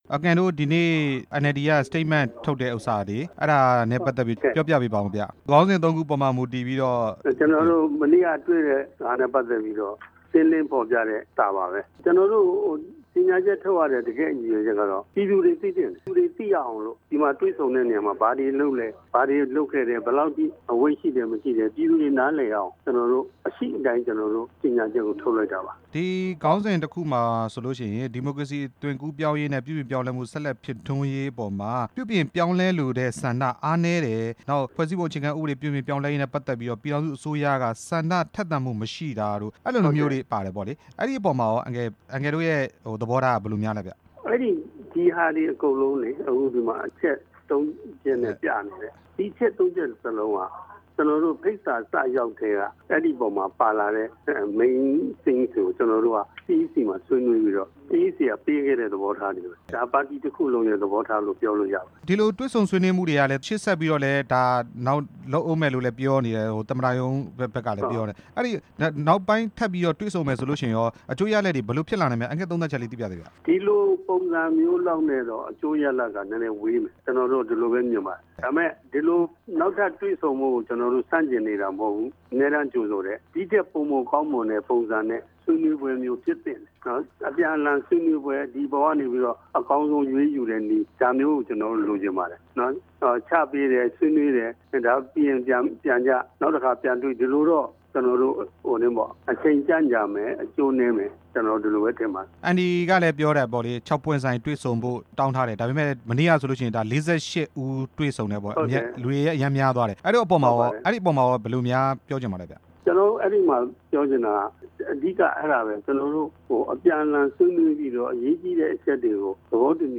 ၄၈ ဦး ဆွေးနွေးပွဲ NLD ထုတ်ပြန်တဲ့အကြောင်း မေးမြန်းချက်